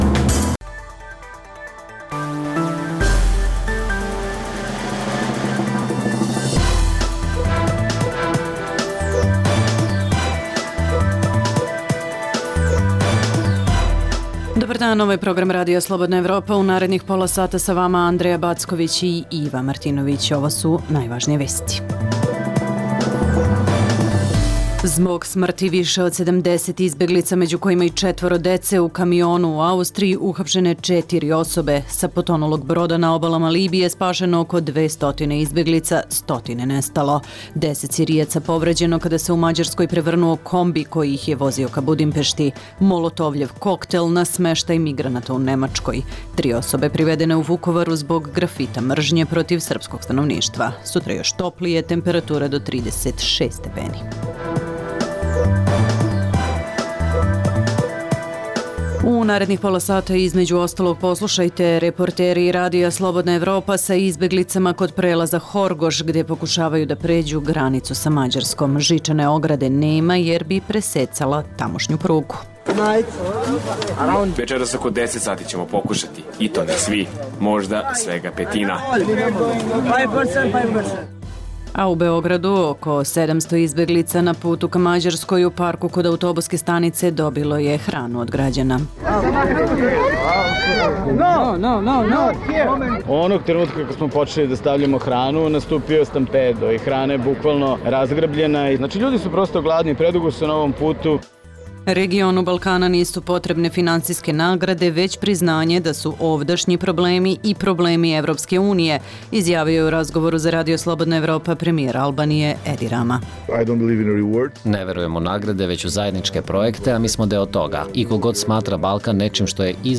- Sa potonulog broda na obalama Libije spašeno oko 200 izbeglica, stotine nestalo. - Reporteri RSE sa izbeglicama kod prelaza Horgoš, gde pokušavaju da pređu granicu sa Mađarskom. - Poslušajte i intervju sa premijerom Albanije Edijem Ramom.